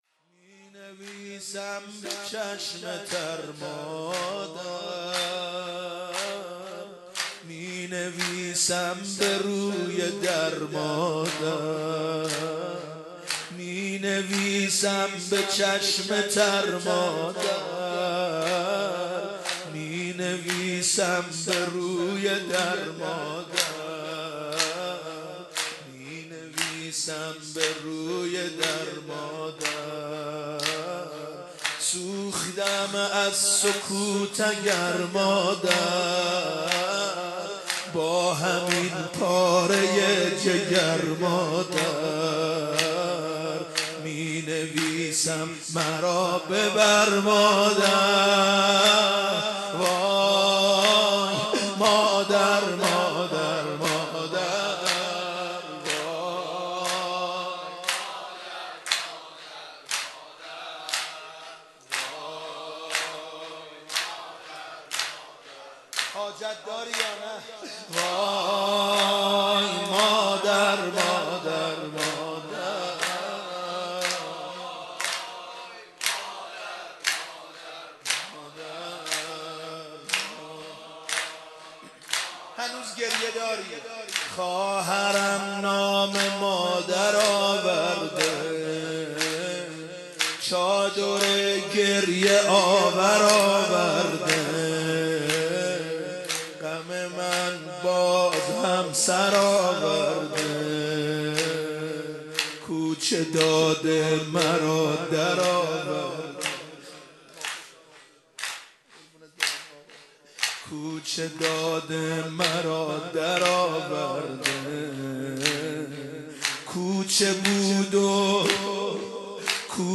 محرم 95